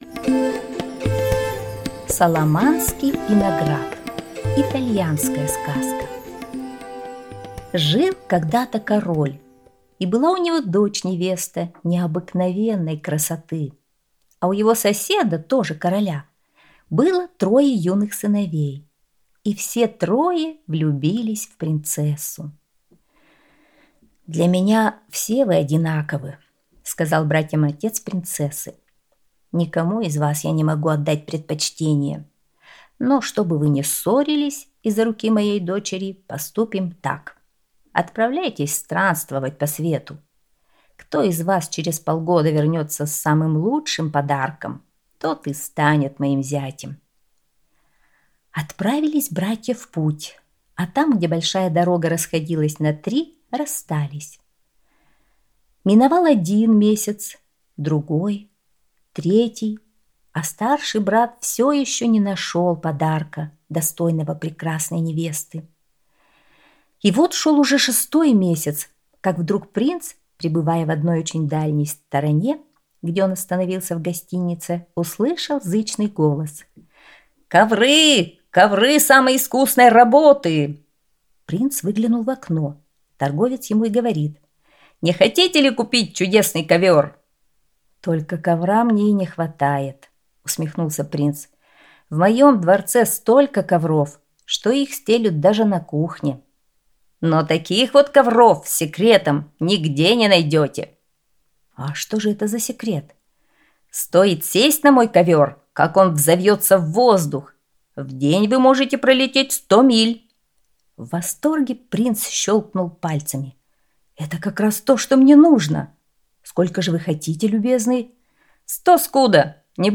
Саламанский виноград – итальянская аудиосказка